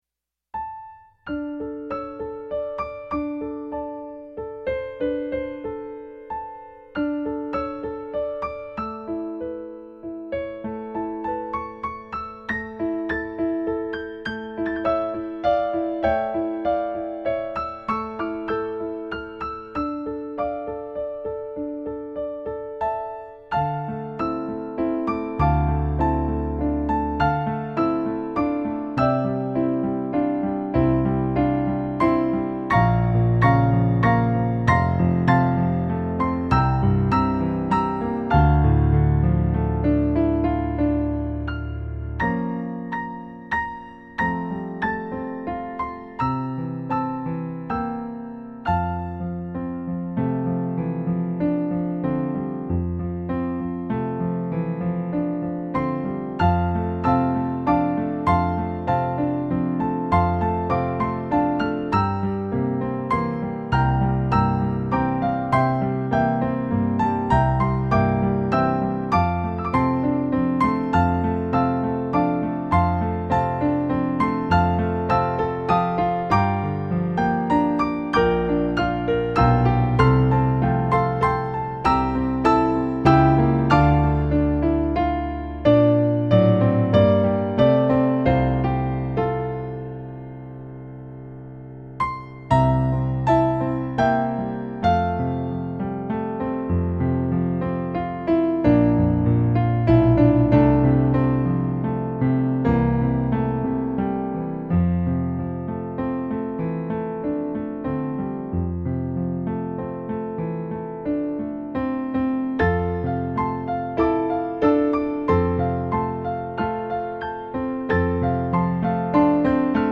peaceful assurance
Hymns